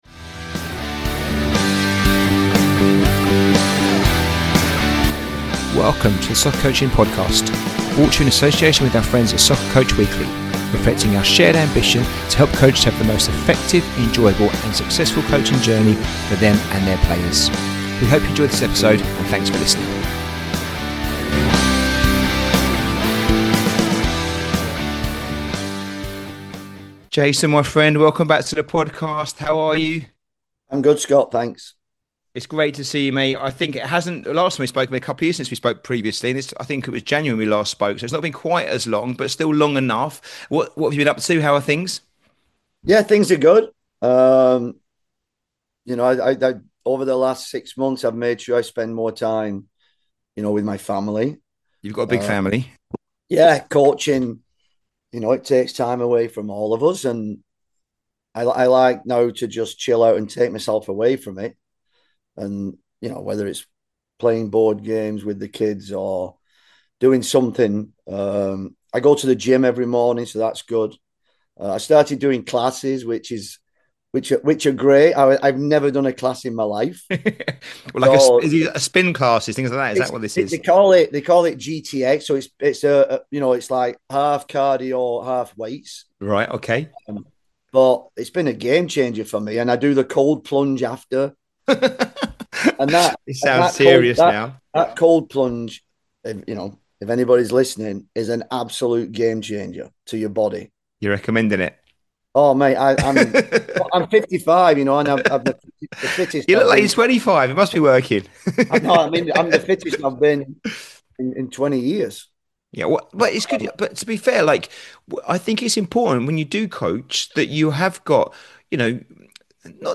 The Importance of Philosophy and Culture, a conversation